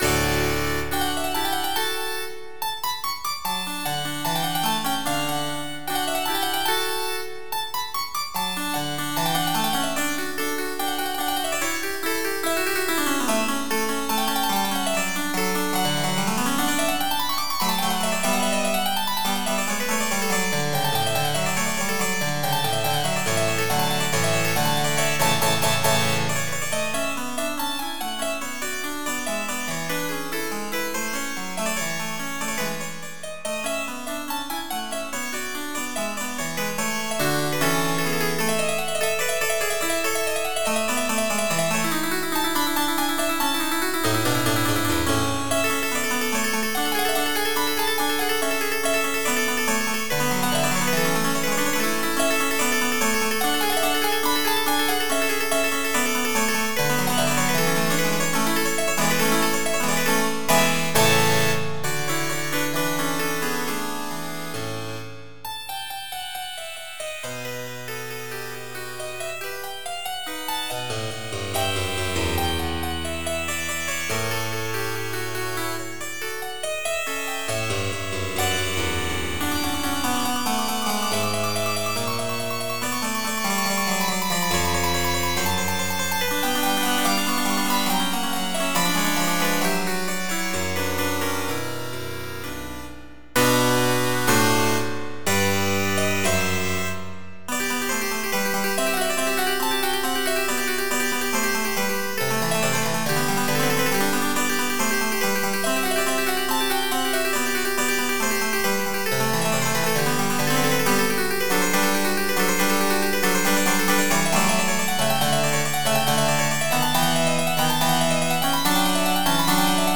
MIDI Music File
MOZART SONATA Type General MIDI